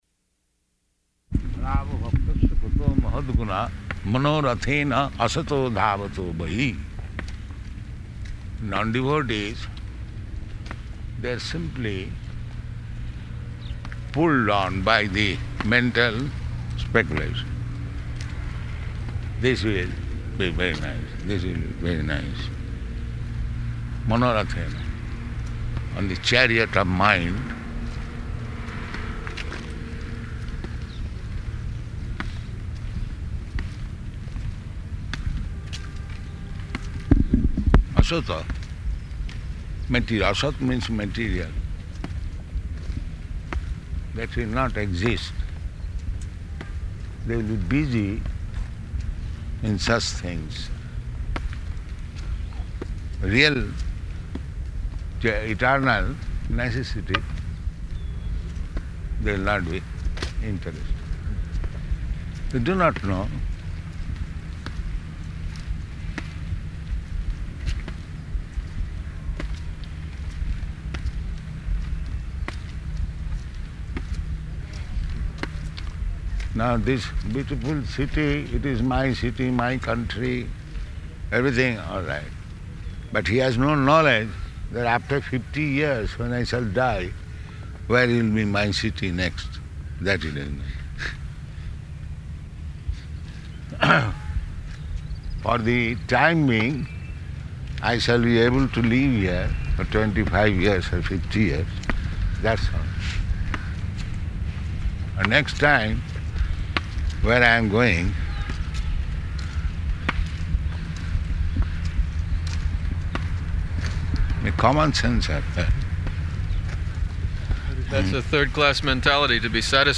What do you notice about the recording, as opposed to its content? Location: Nairobi